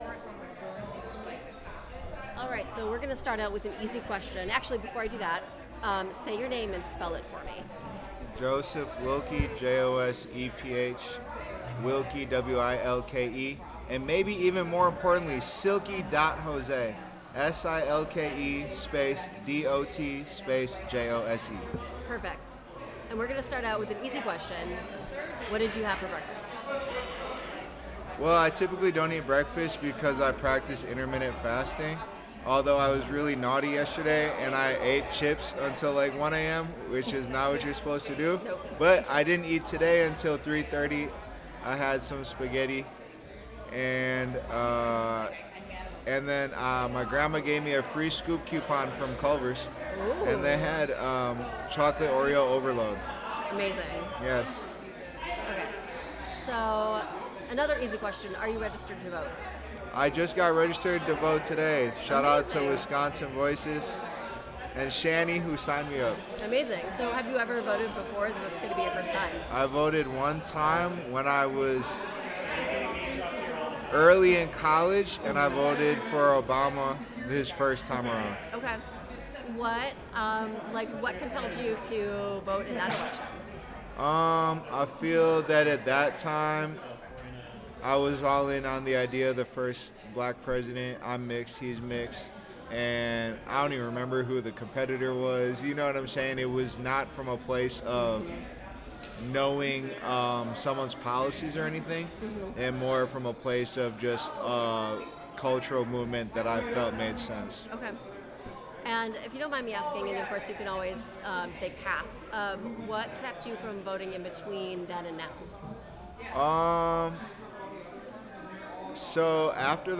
Interview
Location Turner Hall